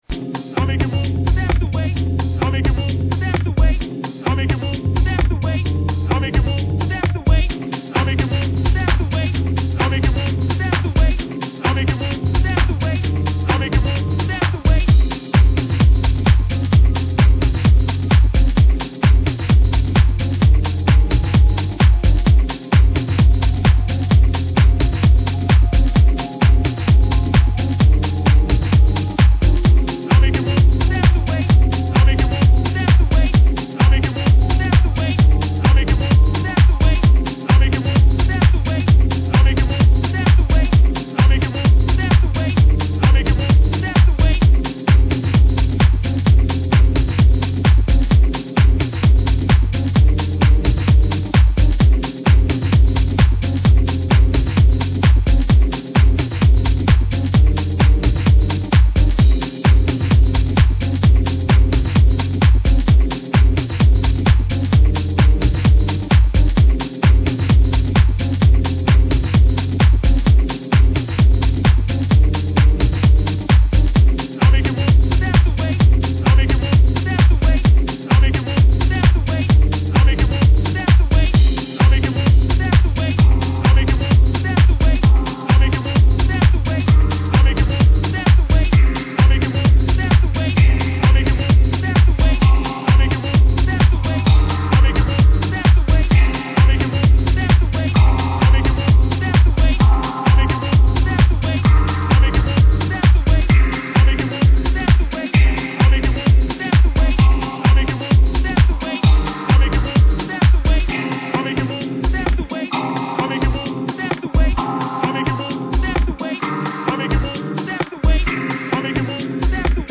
3 groovers for the dancefloor